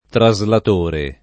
vai all'elenco alfabetico delle voci ingrandisci il carattere 100% rimpicciolisci il carattere stampa invia tramite posta elettronica codividi su Facebook traslatore [ tra @ lat 1 re ] (antiq. translatore ) s. m.; f. ‑trice